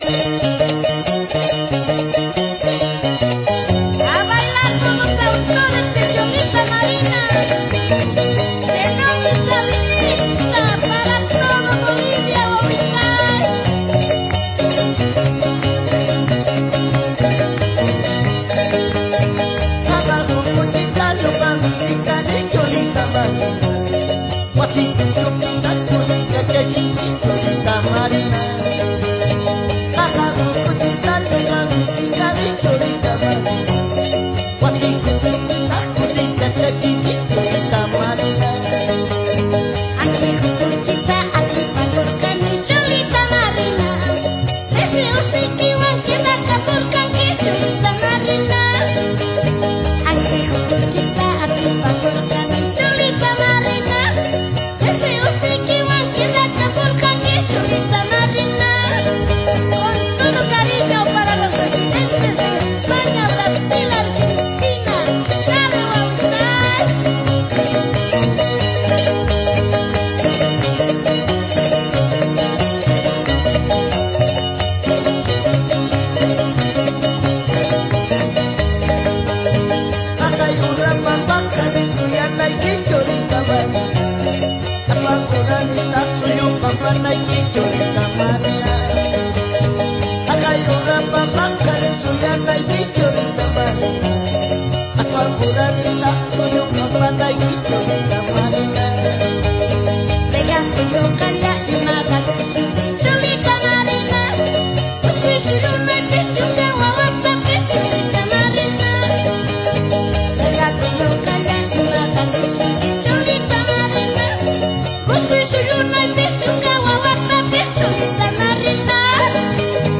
Huayno Aymara Moderno